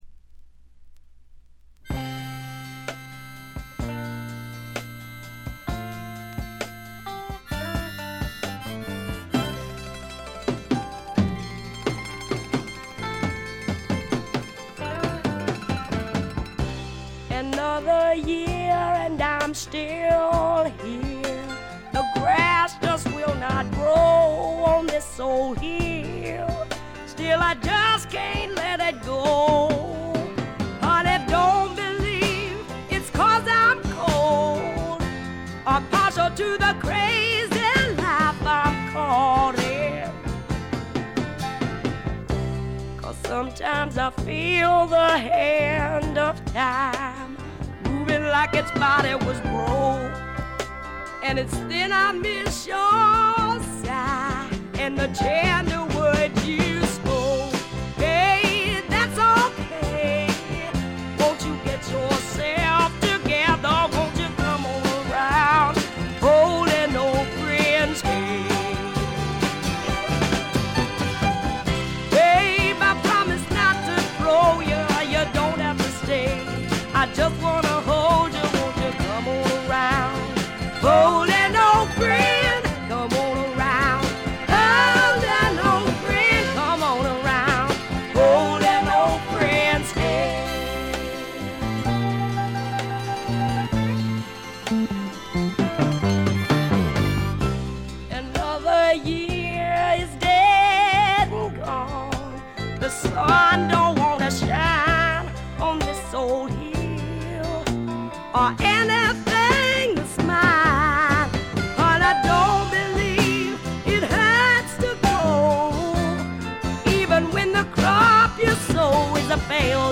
ほとんどノイズ感無し。
超重量級スワンプ名作。
試聴曲は現品からの取り込み音源です。